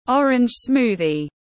Sinh tố cam tiếng anh gọi là orange smoothie, phiên âm tiếng anh đọc là /ˈɒr.ɪndʒ ˈsmuː.ði/
Orange smoothie /ˈɒr.ɪndʒ ˈsmuː.ði/